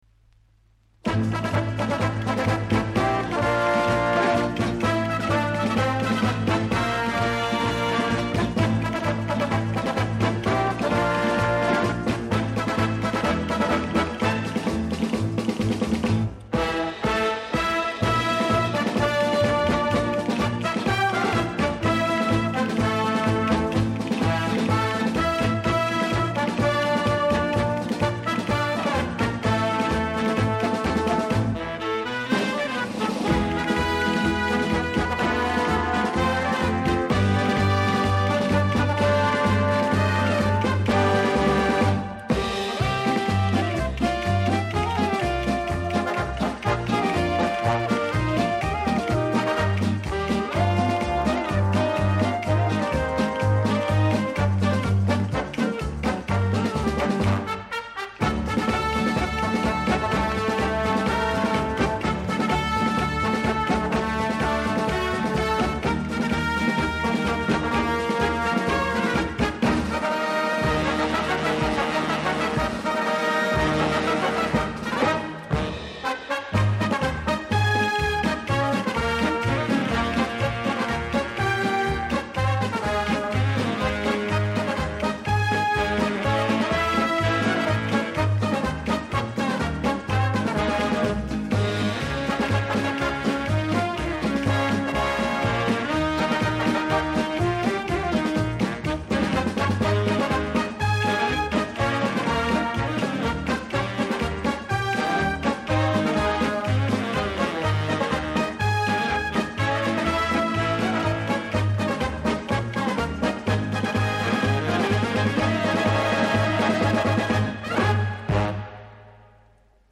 Genre:Easy Listening,Instrumental